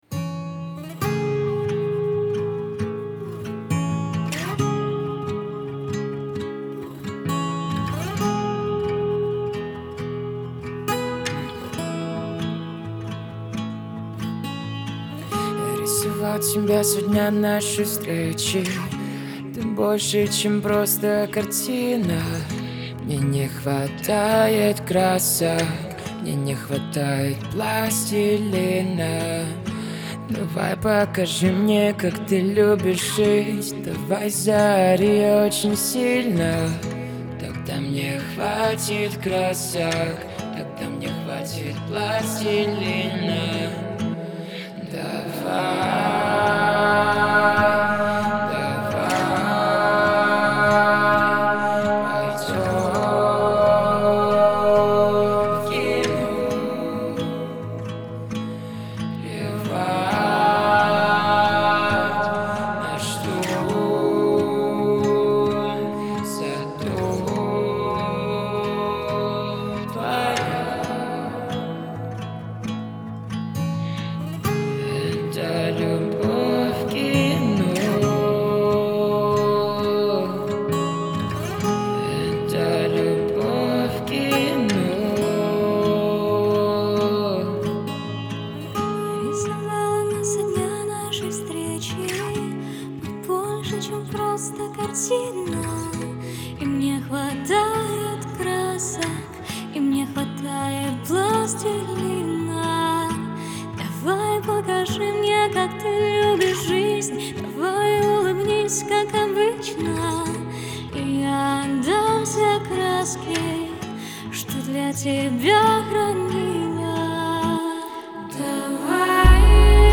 энергичная и динамичная композиция
выполненная в жанре рок.